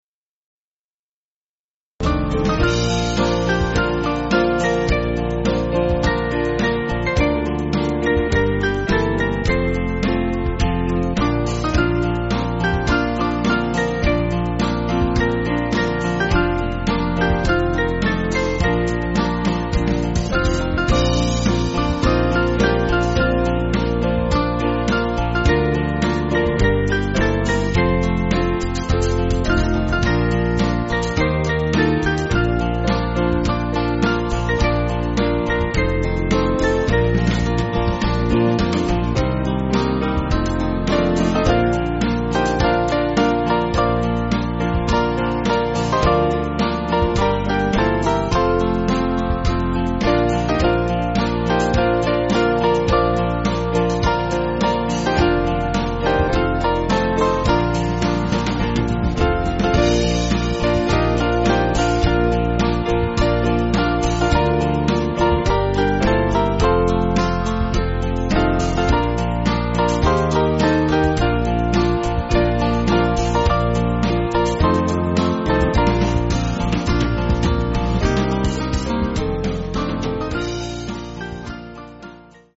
Small Band
(CM)   4/Bb